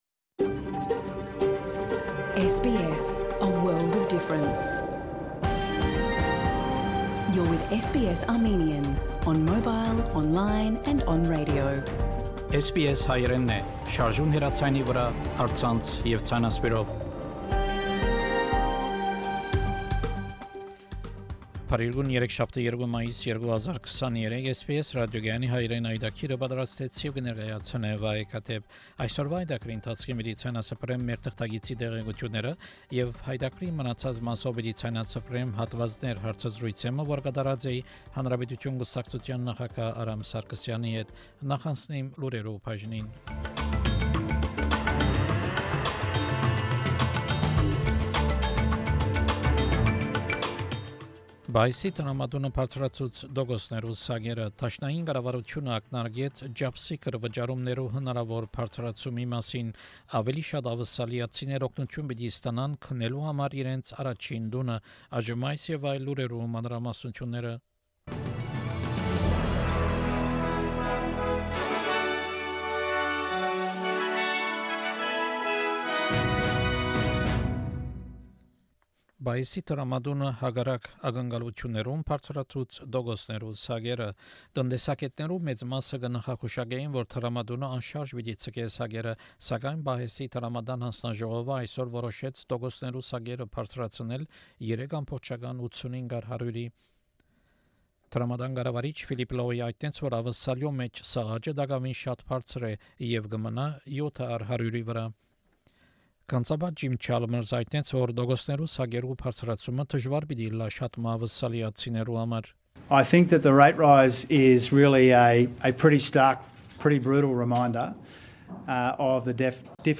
SBS Armenian news bulletin – 2 May 2023
SBS Armenian news bulletin from 2 May 2023 program.